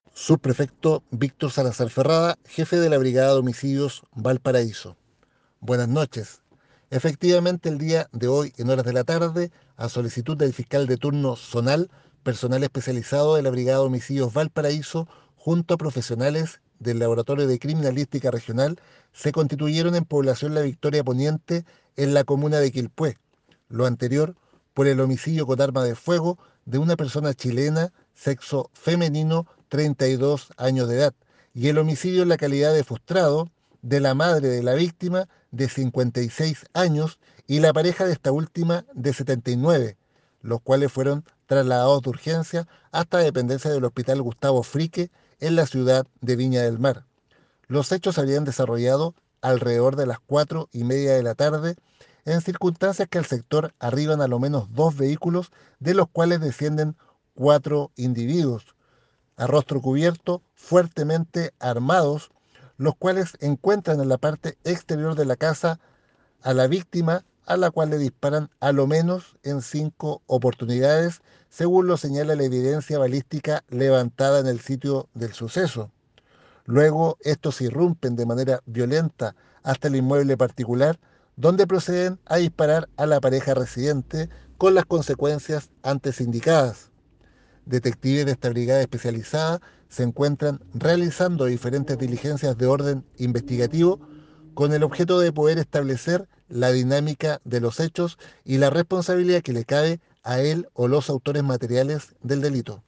Puedes escuchar acá el relato de los hechos en voz del subprefecto